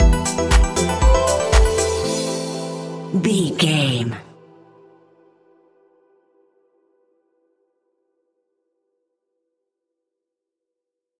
Aeolian/Minor
dark
futuristic
epic
groovy
drums
drum machine
synthesiser
electric piano
house
electro house
synth leads
synth bass